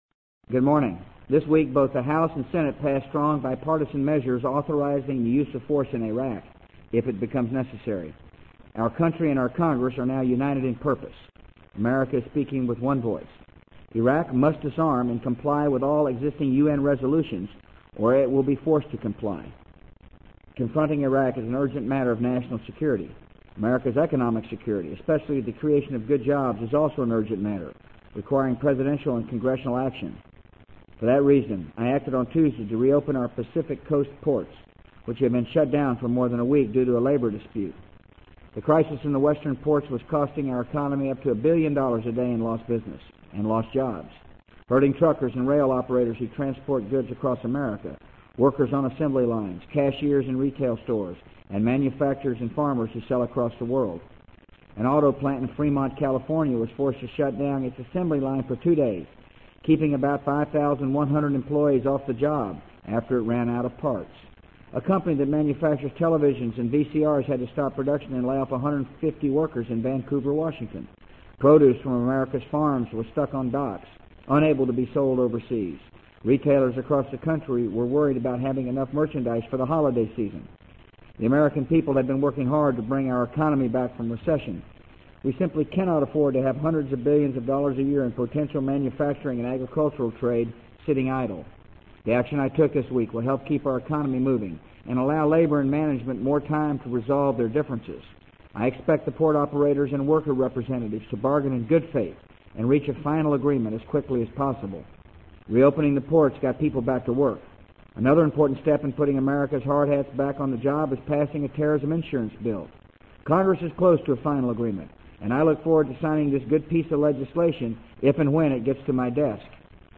【美国总统George W. Bush电台演讲】2002-10-12 听力文件下载—在线英语听力室